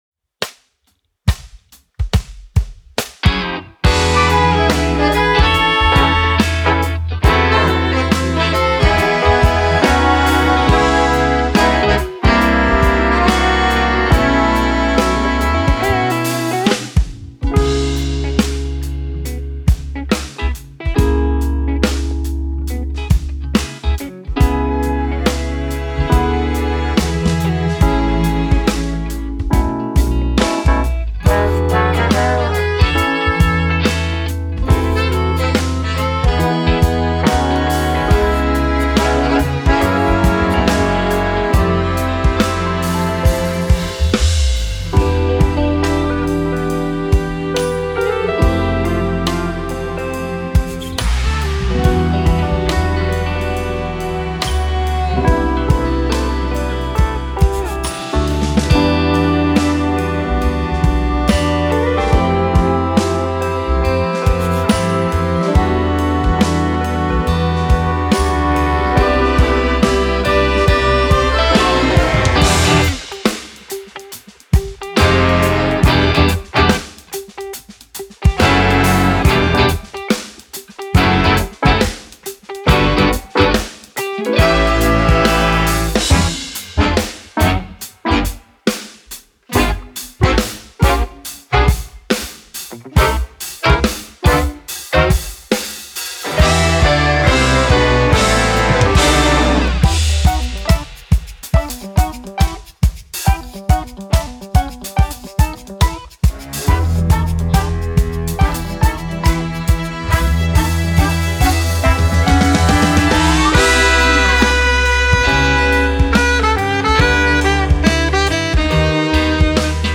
Band: Schlagzeug, Bass, Keys, Gitarre 2/3, Gesang/Rap/Chor
Blech: Trompete 1+2, Posaune 1+2
Streicher: Violine 1, Violine 2, Bratsche, Cello